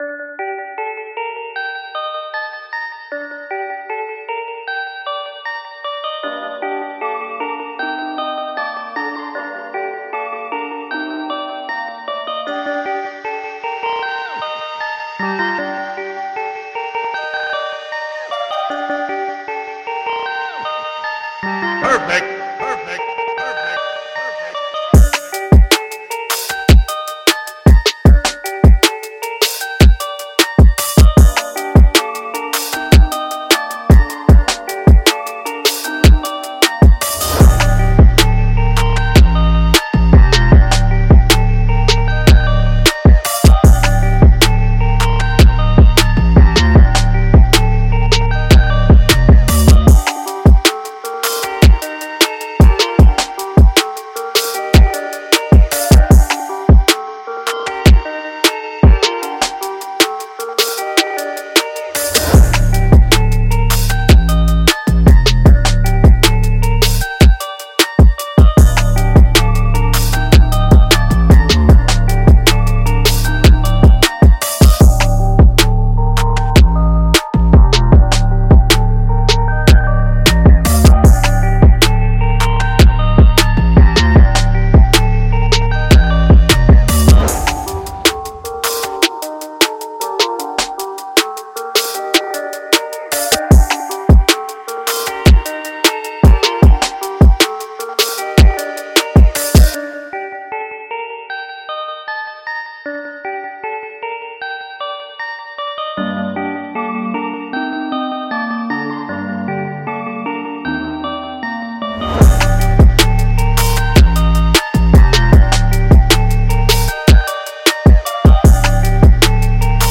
描述：嘻哈饶舌说唱|激励
Tag: 808 贝司 合成器